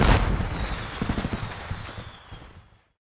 firework_explosion_03.ogg